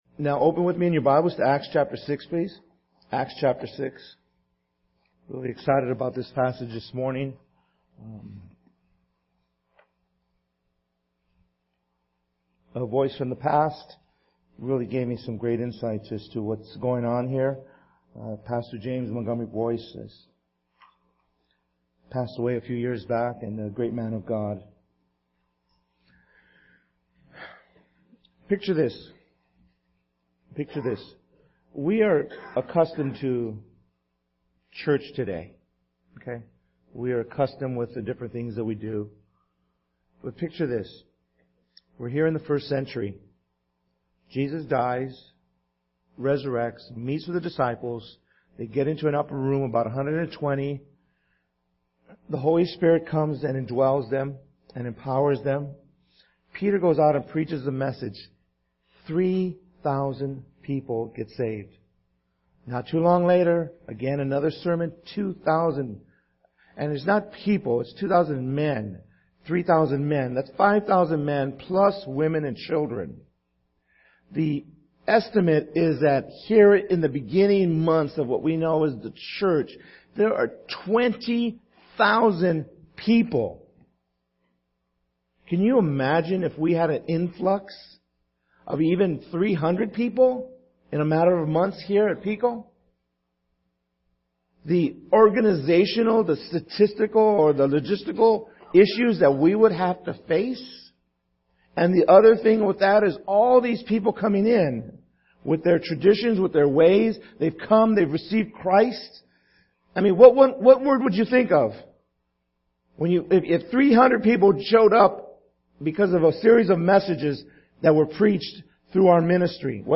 December 28th 2014 Sermon